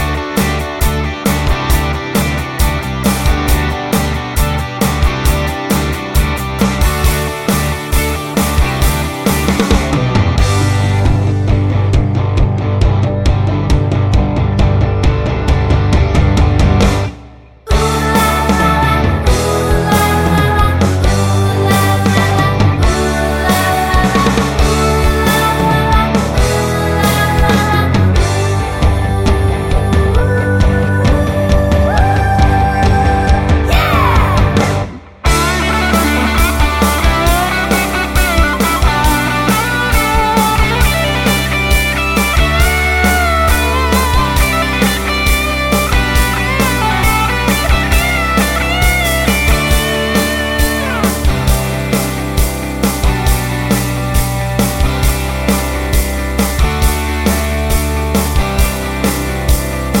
no Backing Vocals Musicals 4:13 Buy £1.50